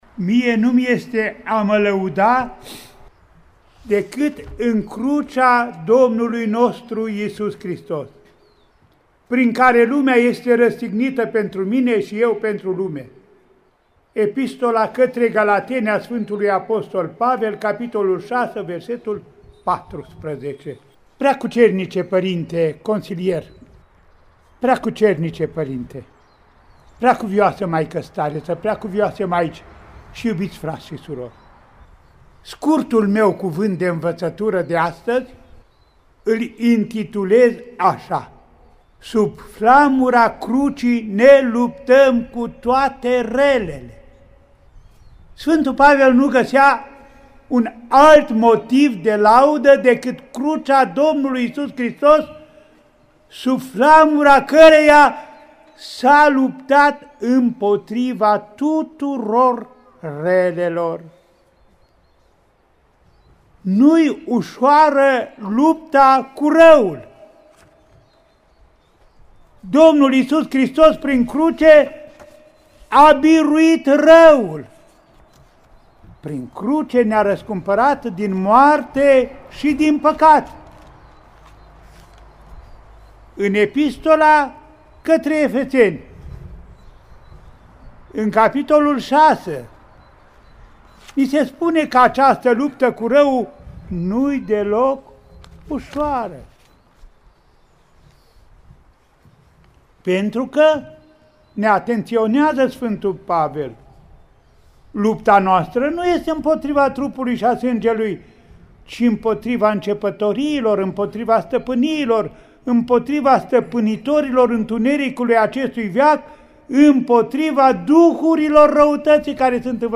În Duminica a III-a din Postul Sfintelor Paști (a Sfintei Cruci), 22 martie 2020, Înaltpreasfinţitul Părinte Andrei, Arhiepiscopul Vadului, Feleacului şi Clujului şi Mitropolitul Clujului, Maramureşului şi Sălajului, a oficiat Sfânta Liturghie la Mănăstirea „Nașterea Maicii Domnului” din localitatea clujeană Ciucea.
Sfânta Liturghie a fost oficiată la altarul de vară al așezământului monahal, ca urmare a măsurilor de prevenție şi limitare a răspândirii coronavirusului.